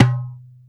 R8Darbuka1.wav